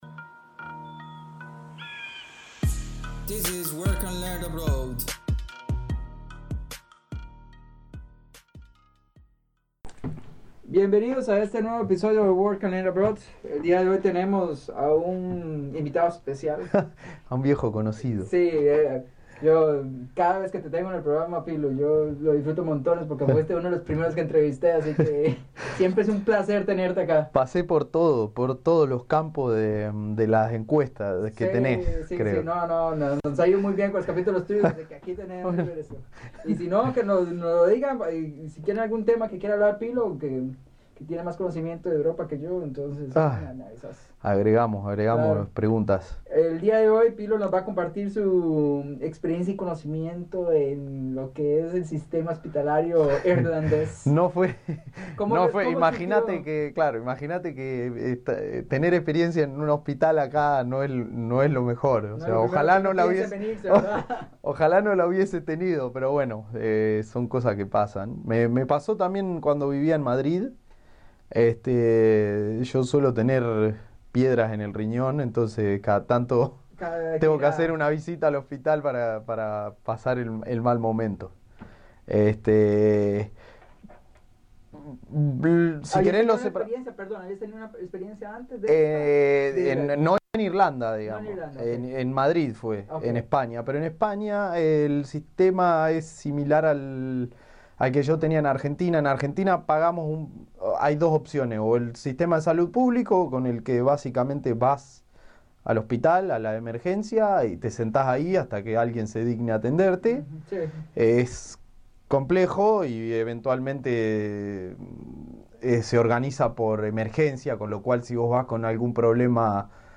Trabajar en el extranjero Entrevista